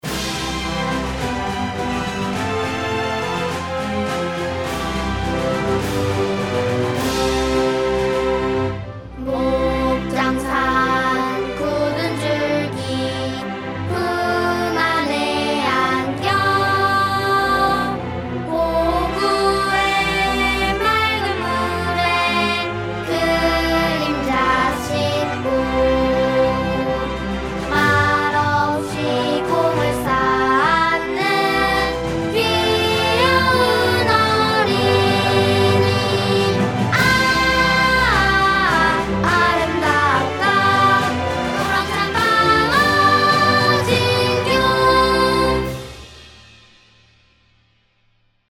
방어진초등학교 교가 음원 :울산교육디지털박물관